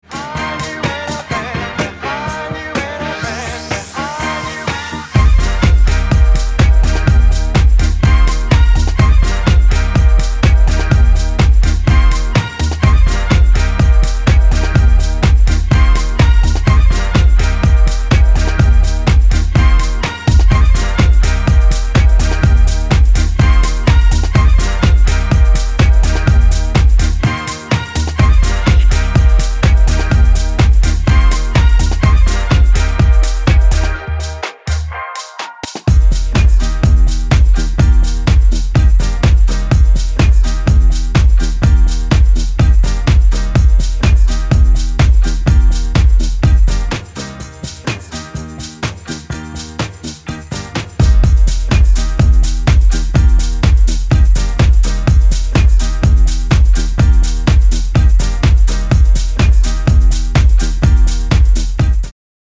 各曲、煽りに煽るフィルター使いとマッシヴなボトムの威力が光ります。